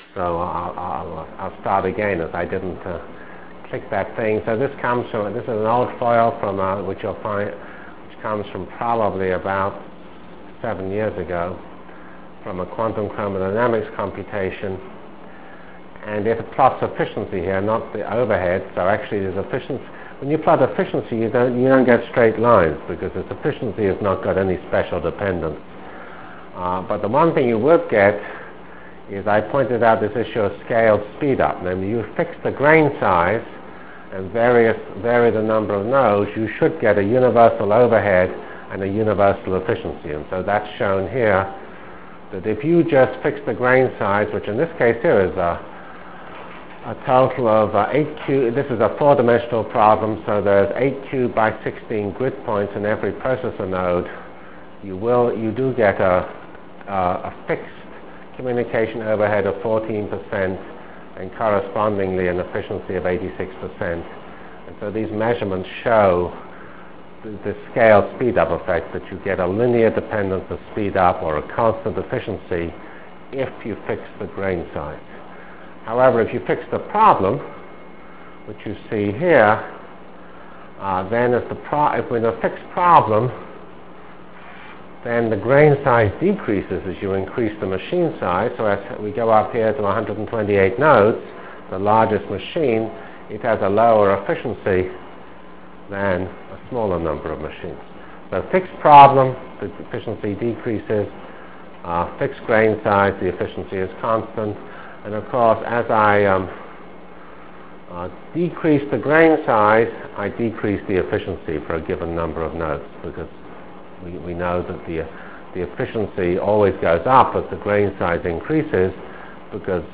From CPS615-Lecture on Performance(end) and Computer Technologies(start)